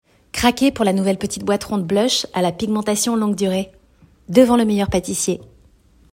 Bande démo VOIX